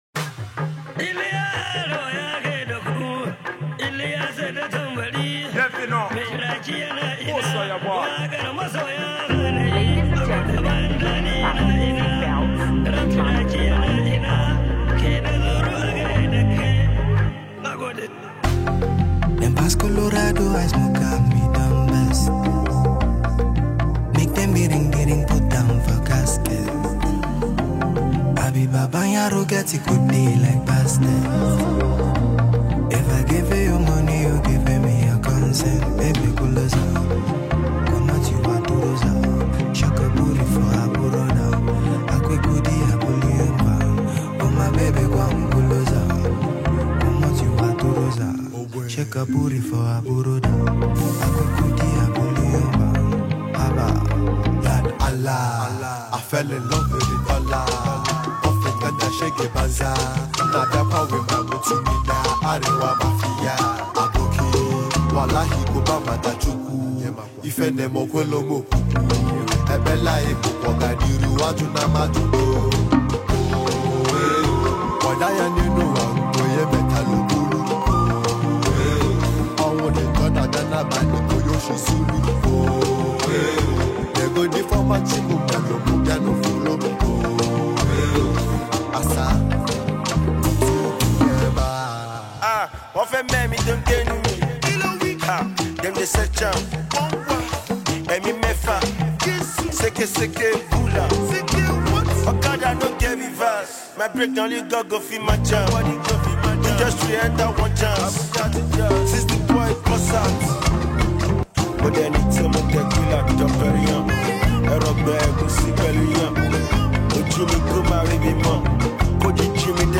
street-hop